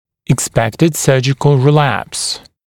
[ɪk’spektɪd ‘sɜːʤɪkl rɪ’læps] [ek-] [ик’спэктид ‘сё:джикл ри’лэпс] [эк-] ожидаемый рецидив после хирургической операции